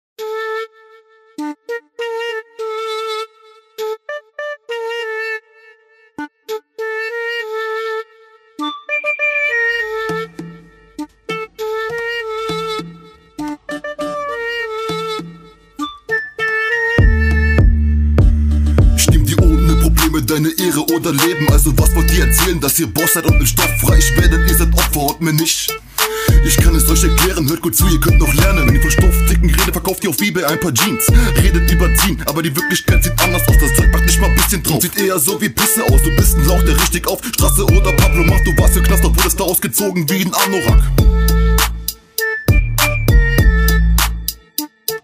Also ich muss sagen Beat ist echt besonders.
Heyho, auf gehts: Fette Stimme digga, Potenzial am start.